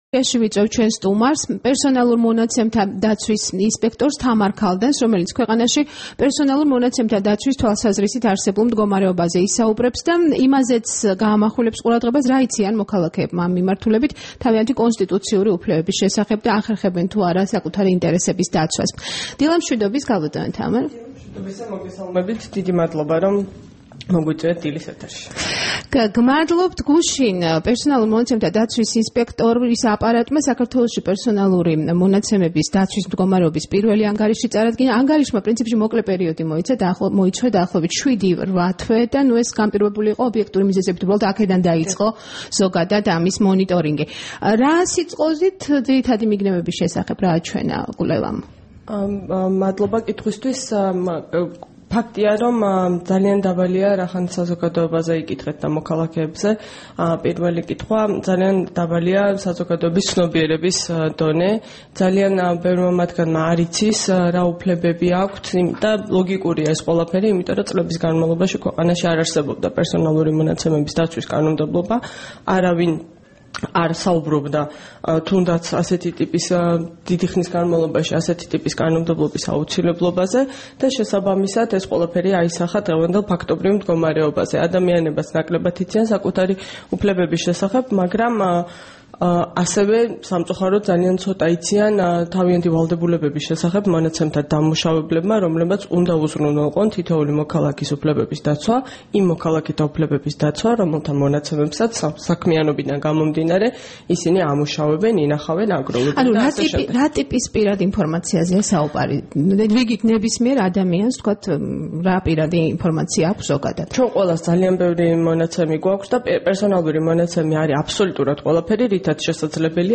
20 მარტს რადიო თავისუფლების დილის გადაცემის სტუმარი იყო თამარ ქალდანი, საქართველოს პერსონალურ მონაცემთა დაცვის ინსპექტორი.
საუბარი თამარ ქალდანთან